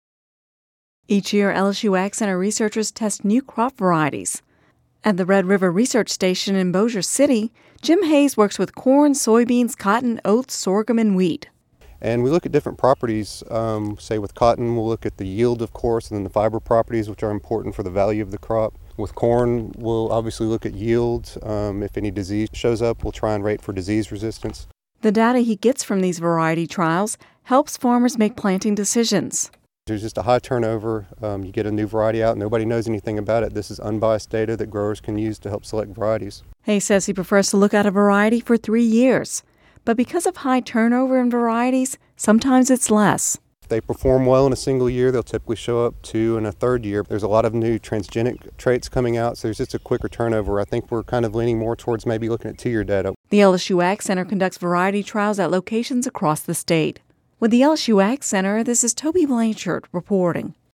(Radio News 07/12/10) Each year, LSU AgCenter researchers test new crop varieties.